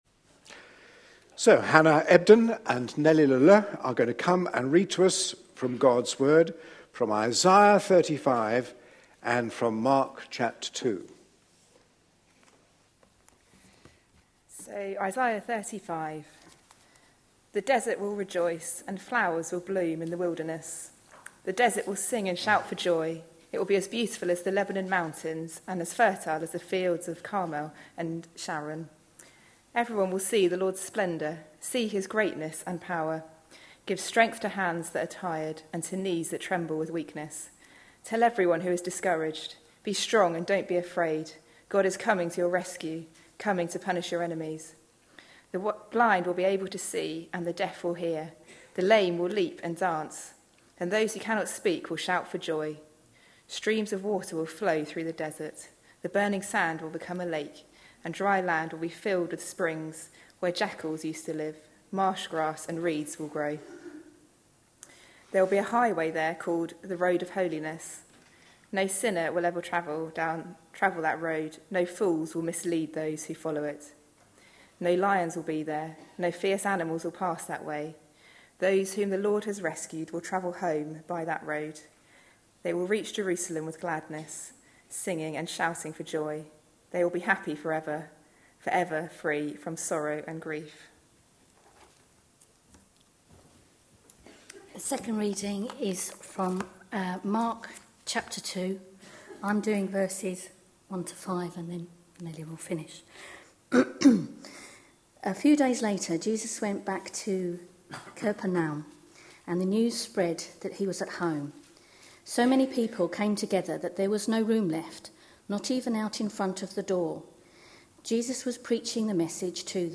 A sermon preached on 13th October, 2013, as part of our Objections to faith answered! series.